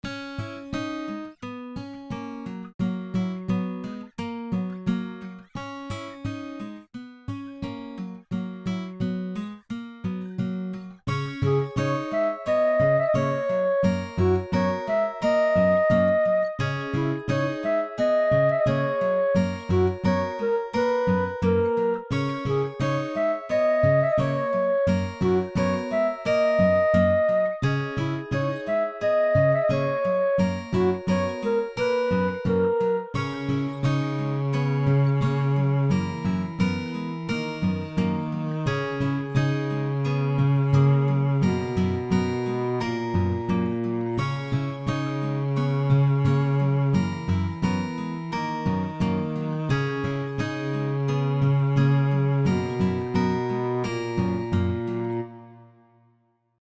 A guitar music.